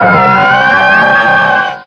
Grito de Milotic.ogg
Grito_de_Milotic.ogg